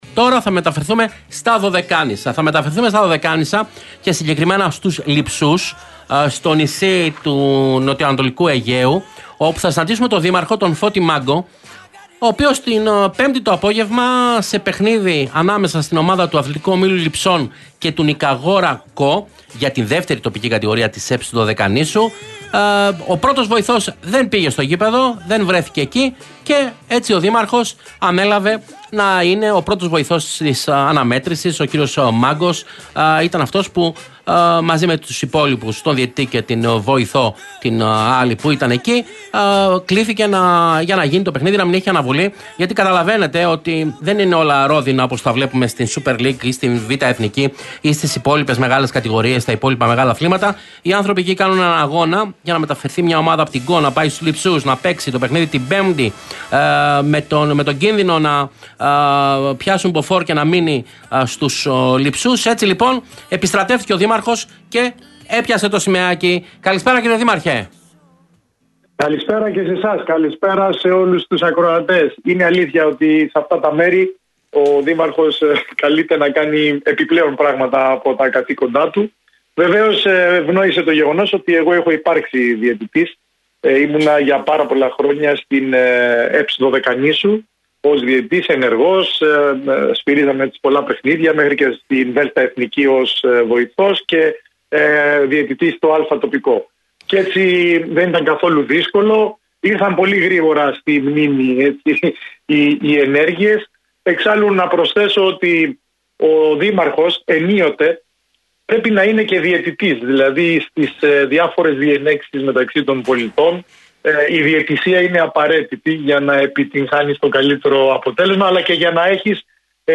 Μιλώντας στον Realfm 97,8 αποκάλυψε ότι και τα προηγούμενα χρόνια σφύριξε σε αρκετά παιχνίδια της ομάδας στο νησί, αφού τα έξοδα είναι υπέρογκα.